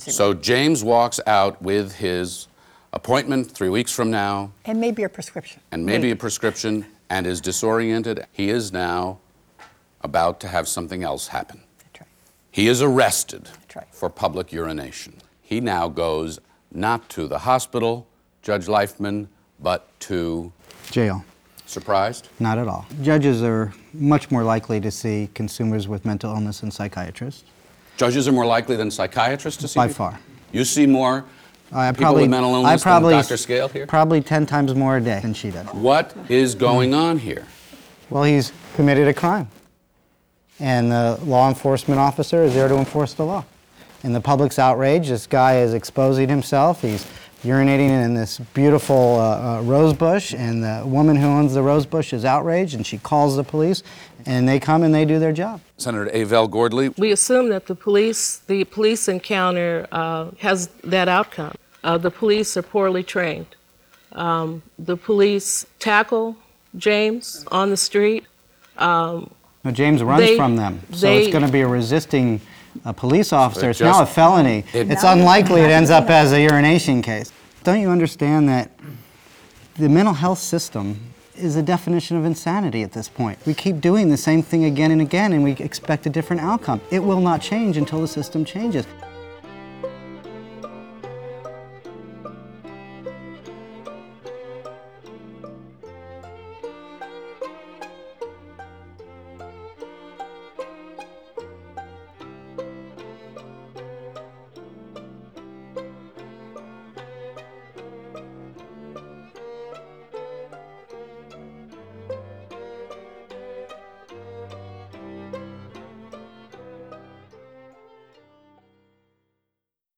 Frank Sesno moderates an engaging conversation sparked by a diverse panel including: Supreme Court Justice Stephen Breyer, Nobel Laureate Dr. Eric Kandel and other physicians, policy makers and mental health professionals, some of whom themselves struggle with mental illness.